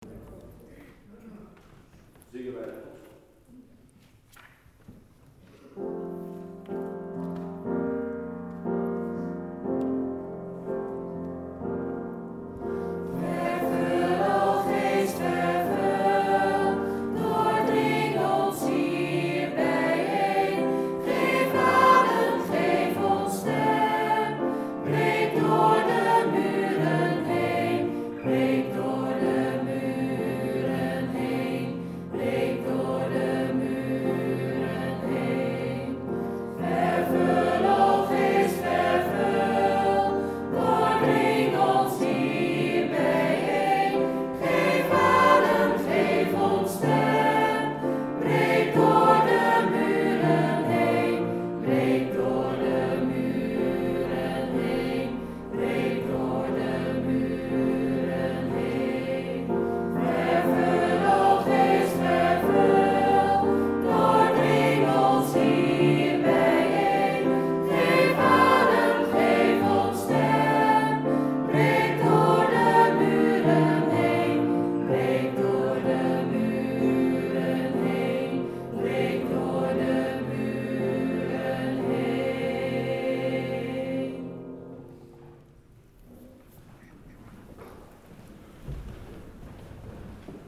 We maken keuzes uit bestaand katholiek jongerenkoor-repertoire,  schrijven zelf teksten en arrangementen op bestaande popliedjes en lenen liedjes van andere koren.